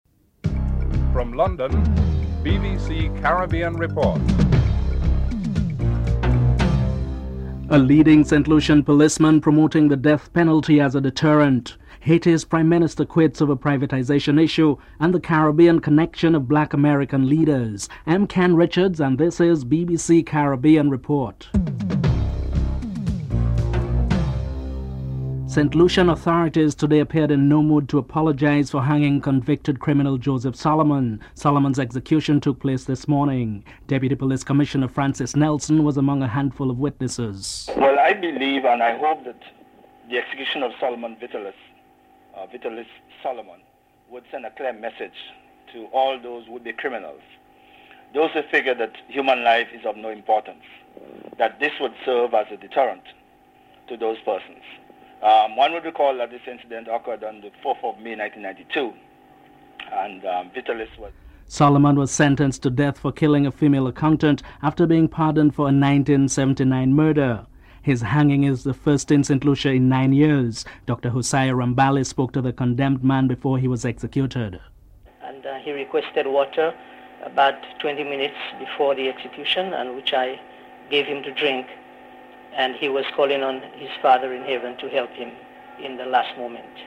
The British Broadcasting Corporation